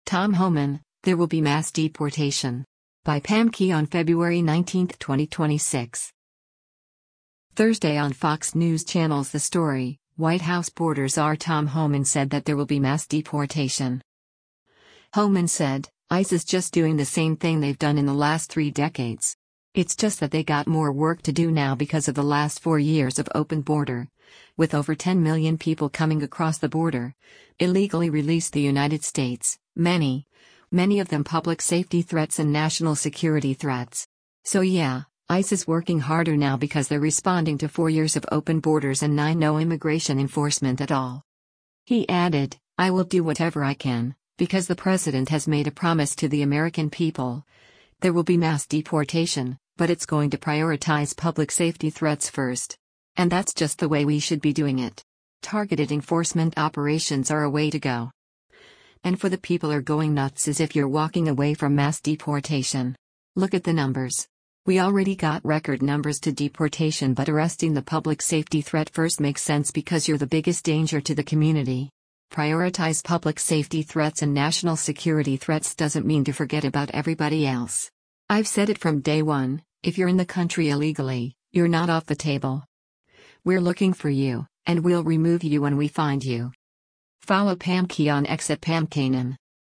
Thursday on Fox News Channel’s “The Story,” White House border czar Tom Homan said that there will be “mass deportation.”